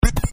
button.mp3.svn-base